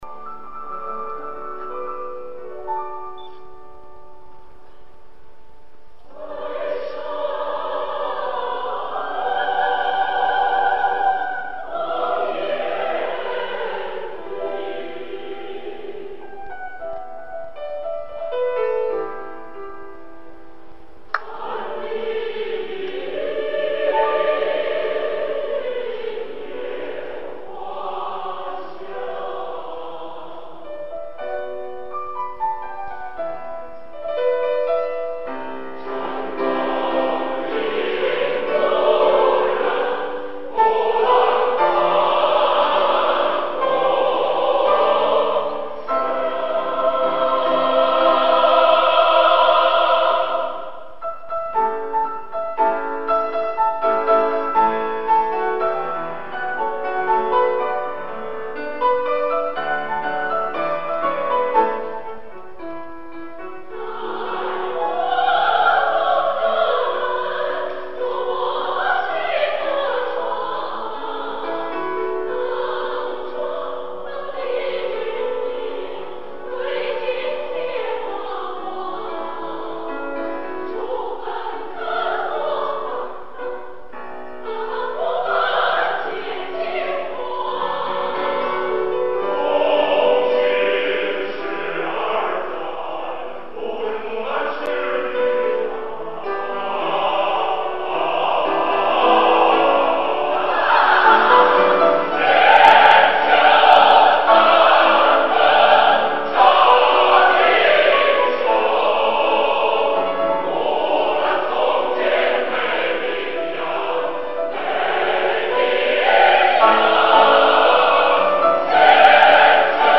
[4/6/2014]优秀合唱作品《木兰从军》2